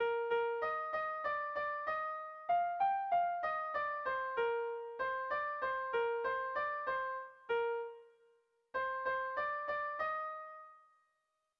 Dantzakoa
Doinuak aurrera darrai neurri desberdinetan.
AB